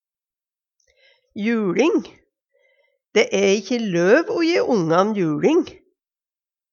juLing - Numedalsmål (en-US)